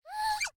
Minecraft Version Minecraft Version 25w18a Latest Release | Latest Snapshot 25w18a / assets / minecraft / sounds / mob / panda / pre_sneeze.ogg Compare With Compare With Latest Release | Latest Snapshot
pre_sneeze.ogg